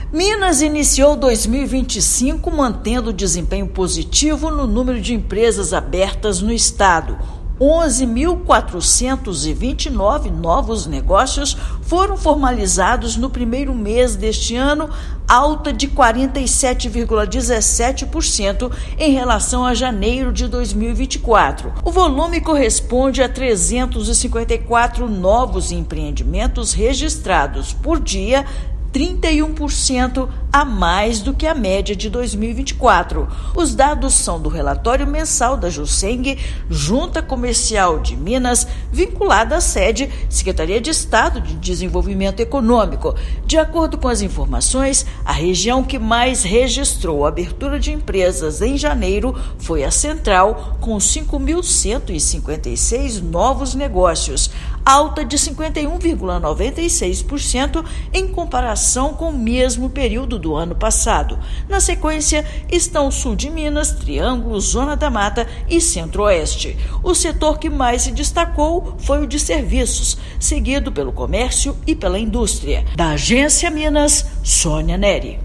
[RÁDIO] Minas Gerais mantém ritmo positivo na abertura de empresas em 2025
Empreendimentos formalizados em janeiro representam alta de 47,17% em comparação com o mesmo mês de 2024. Ouça matéria de rádio.